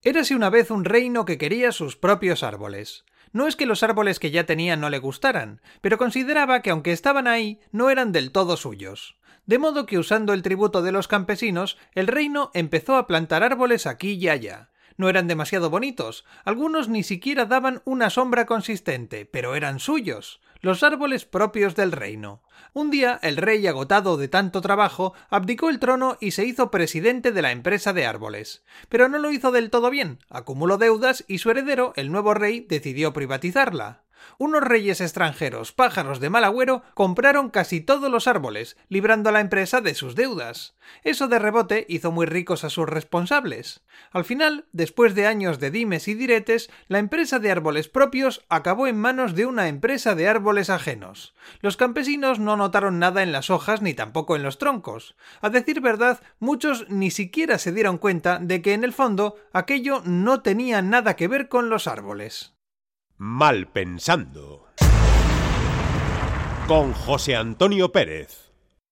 nos cuenta un cuento con sabor a telefonía móvil